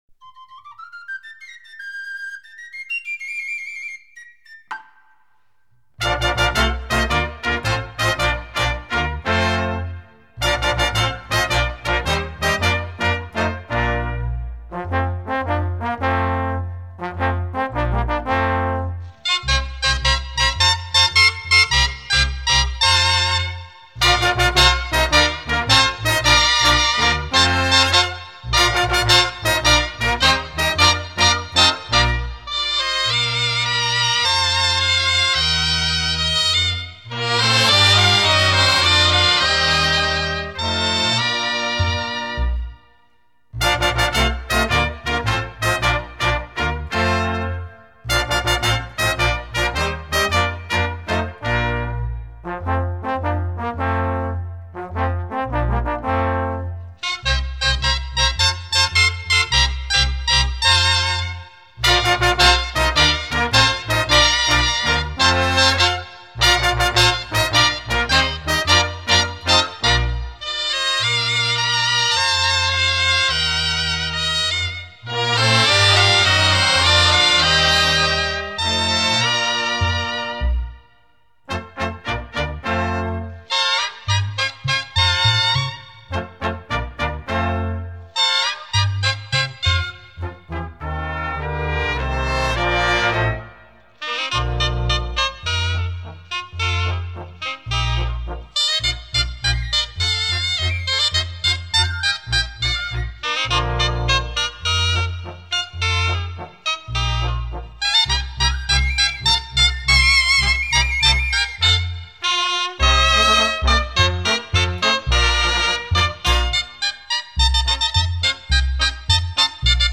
El compositor d’aquesta sardana, Aplec d’Arenys de Mar, Max Havart, neix a Perpinyà el 3 d’abril de 1924 al sí d’una família d’origen anglo-saxó vinguts al nord de França al llarg del segle X i aposentats a la Catalunya nord des de 1823.